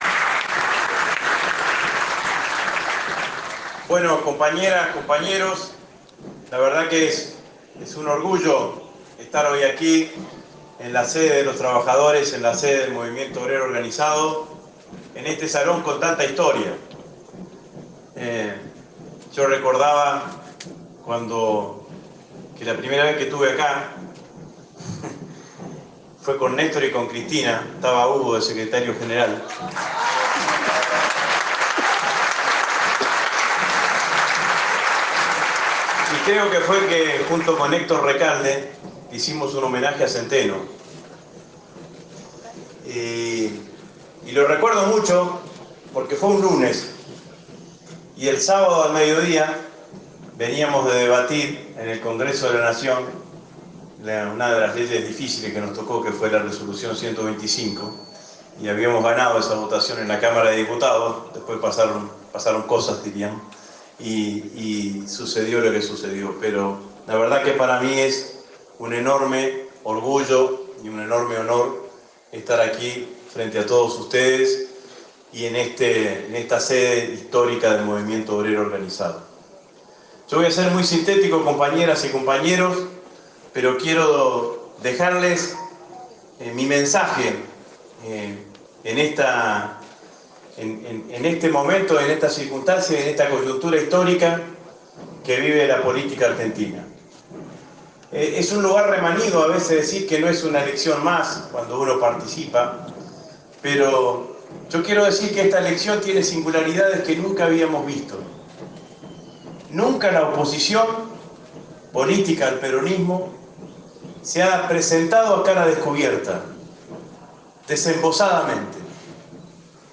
Compartimos el discurso completo: